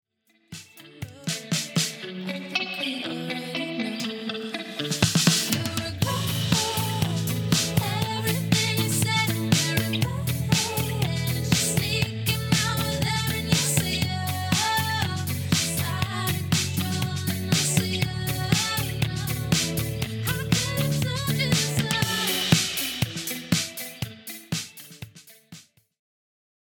Pop/Dance